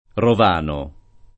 rovano [ rov # no ]